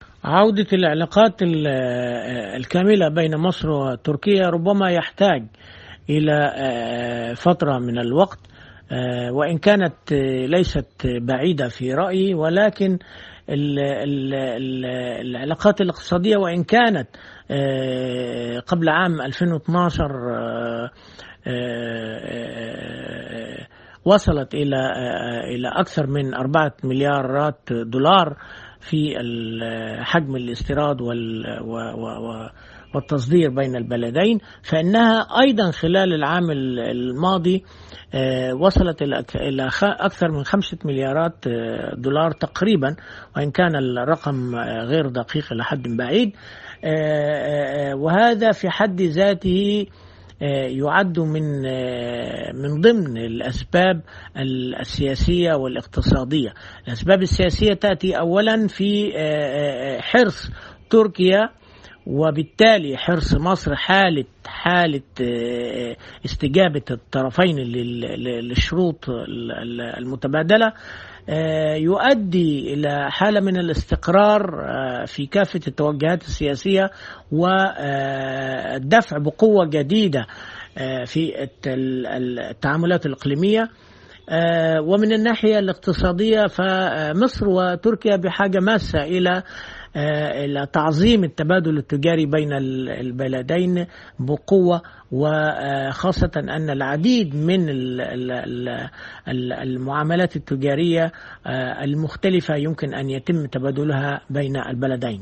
حوار